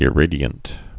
(ĭ-rādē-ənt)